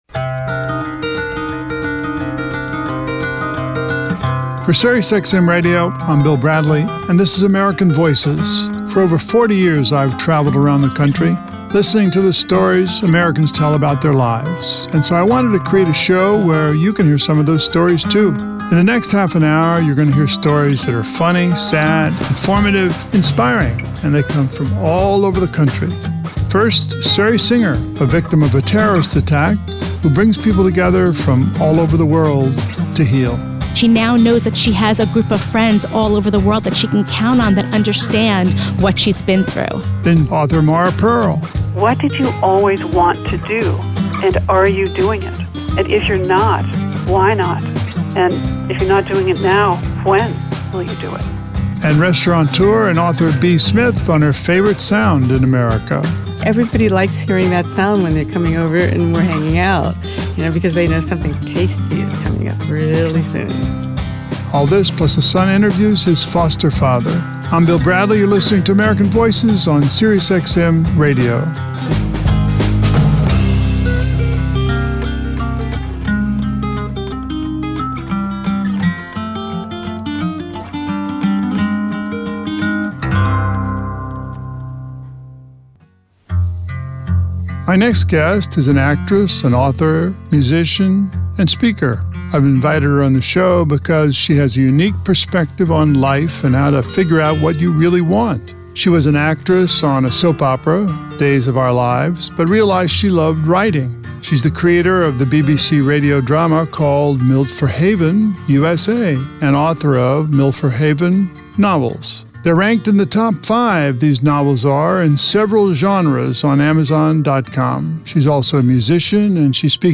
Interview American Voices